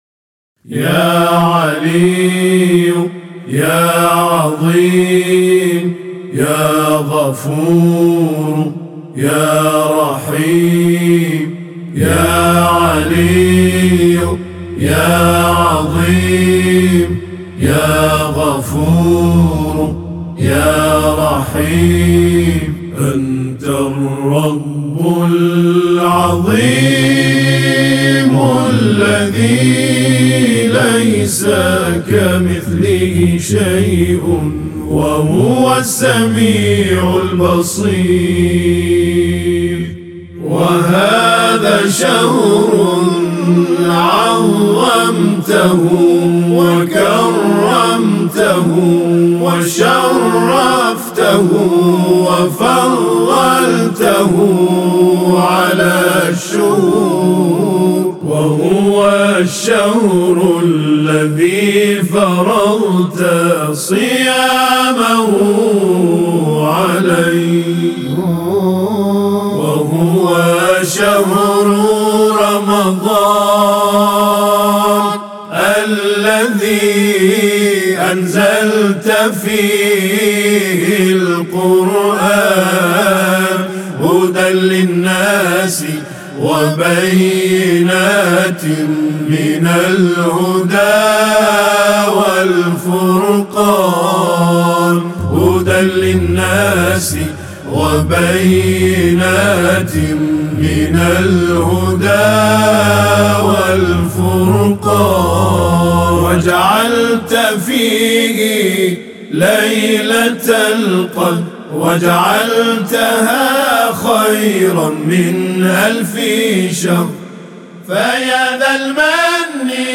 مدیح سرایی